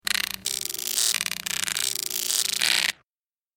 جلوه های صوتی
دانلود صدای حشره 20 از ساعد نیوز با لینک مستقیم و کیفیت بالا